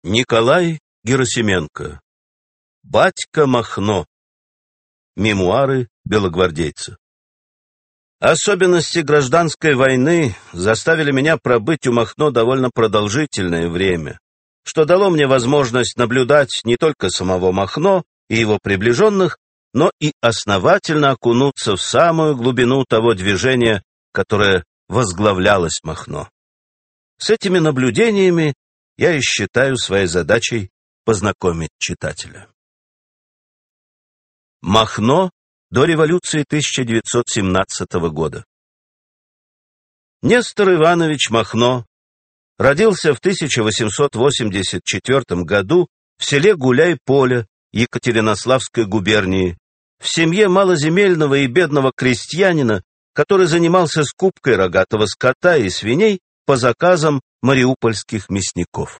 Аудиокнига Батько Махно. Мемуары белогвардейца | Библиотека аудиокниг